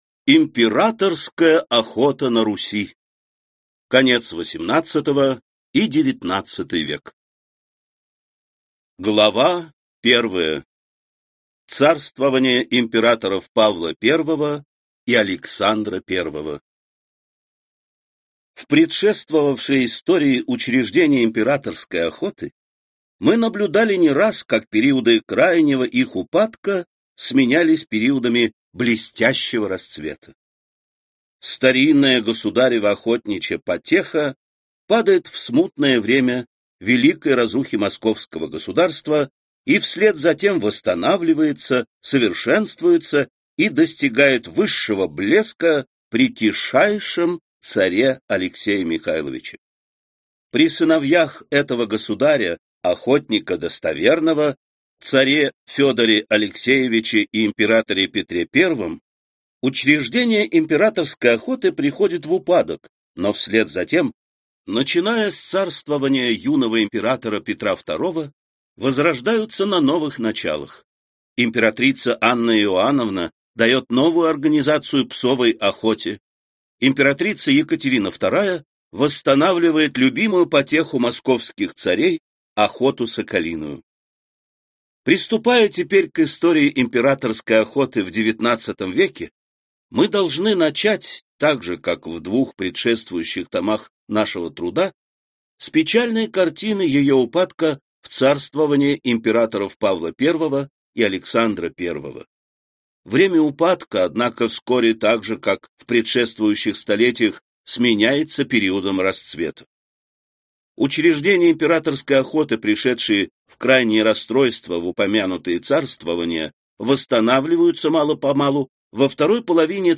Аудиокнига Императорская охота на Руси. Конец XVIII и XIX век | Библиотека аудиокниг